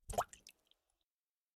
48d440e14c Divergent / mods / Bullet Shell Sounds / gamedata / sounds / bullet_shells / generic_water_6.ogg 33 KiB (Stored with Git LFS) Raw History
generic_water_6.ogg